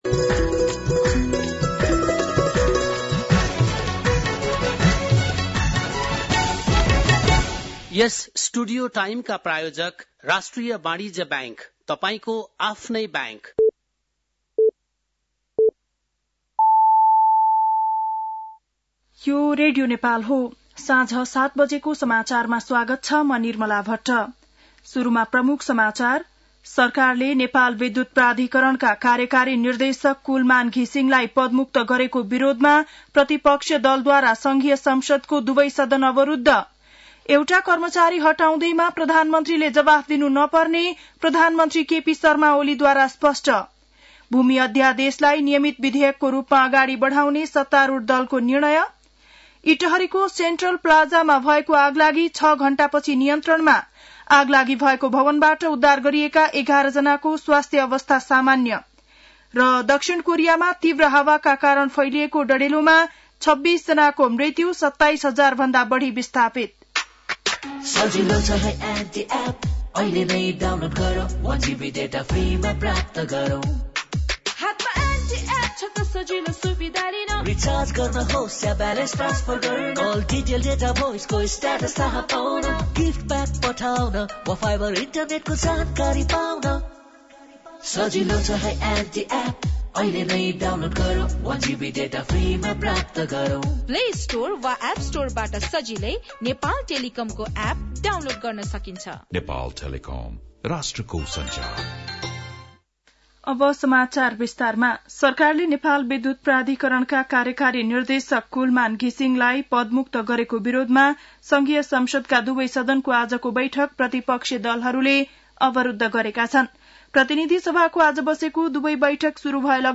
बेलुकी ७ बजेको नेपाली समाचार : १३ चैत , २०८१